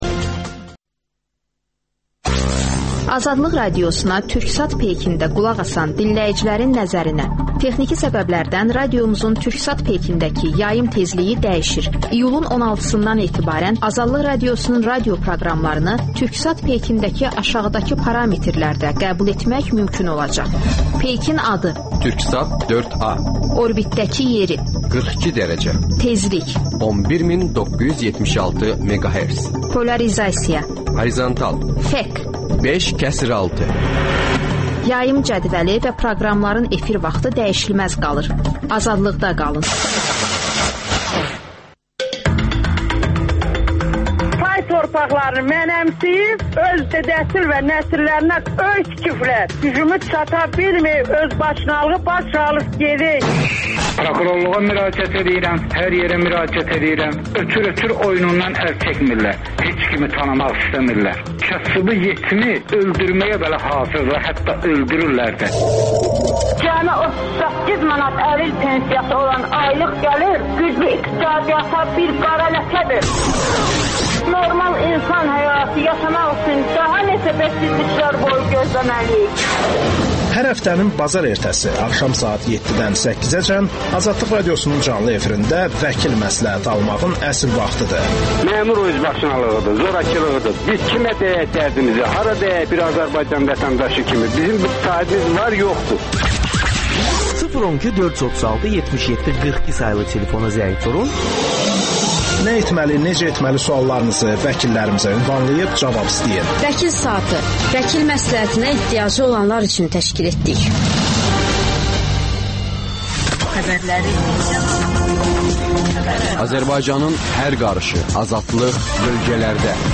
AzadlıqRadiosunun müxbirləri ölkə və dünyadakı bu və başqa olaylardan canlı efirdə söz açırlar.